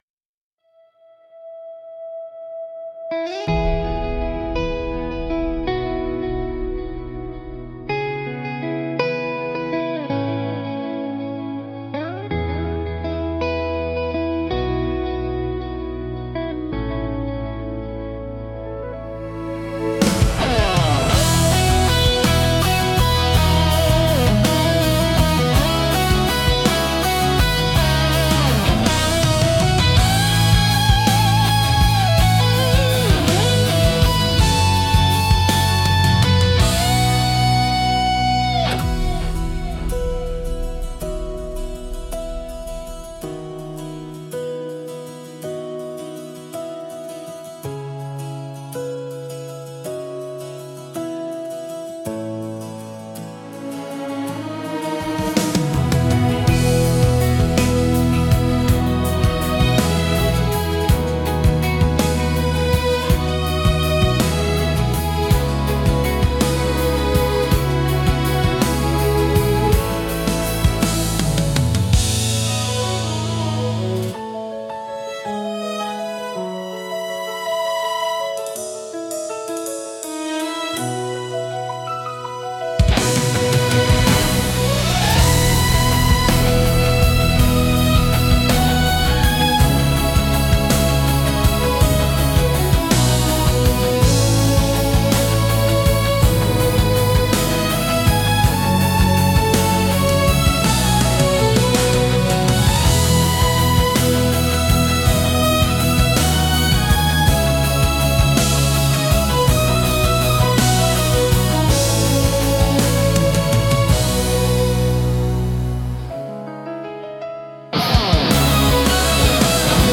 聴く人に新鮮な発見と共感をもたらし、広がりのある壮大な空間を演出するジャンルです。